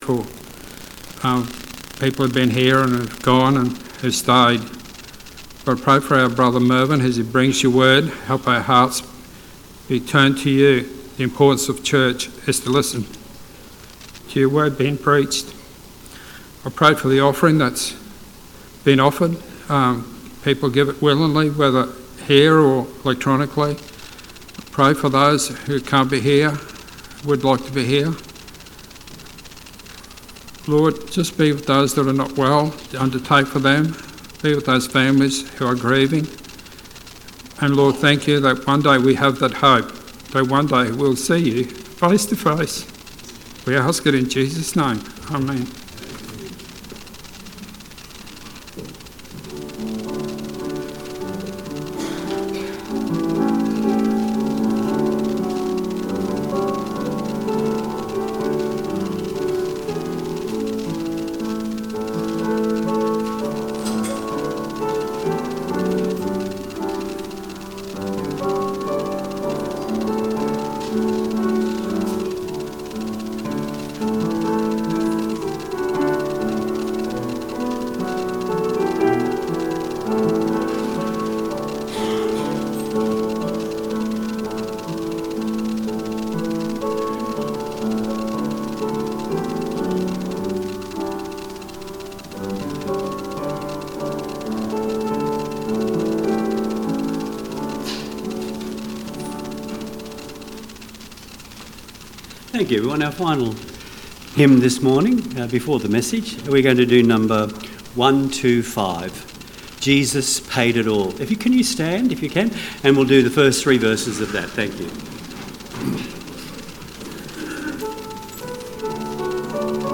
Deuteronomy 28:1-2 Service Type: Sunday AM The final version of this service